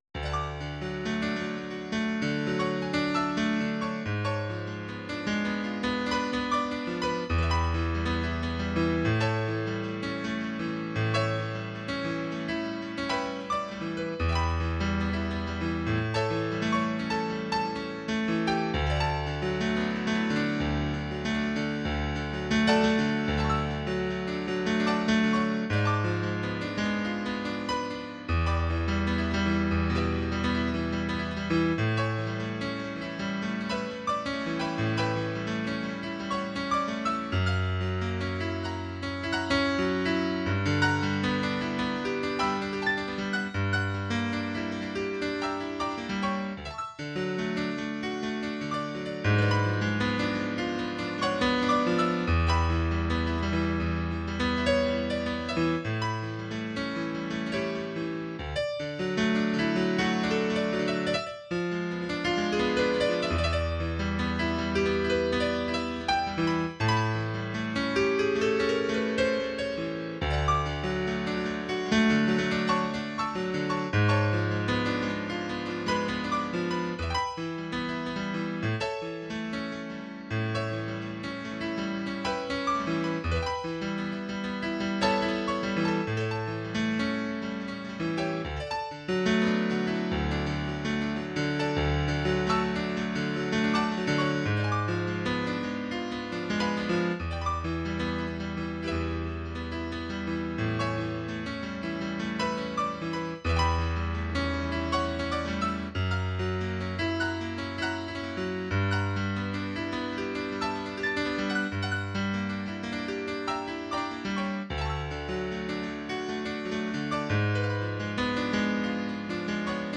Hildegarde / pieza musical